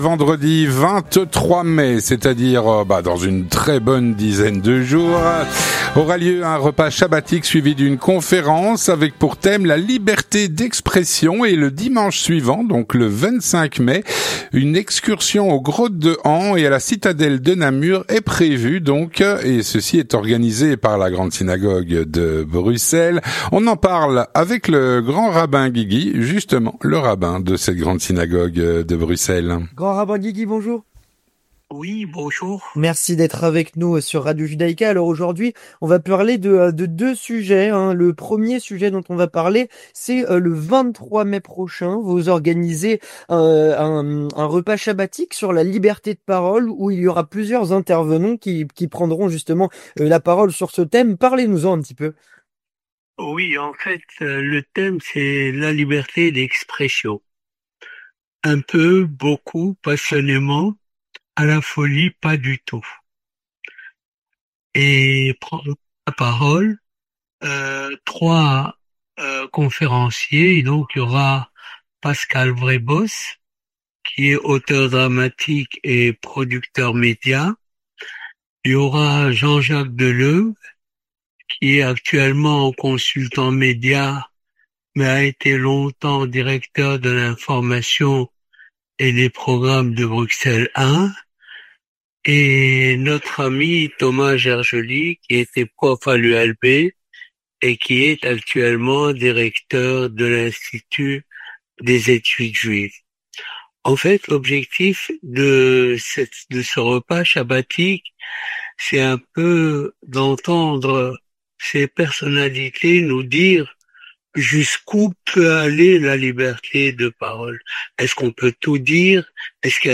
Avec Albert Guigui, Grand Rabbin de Bruxelles.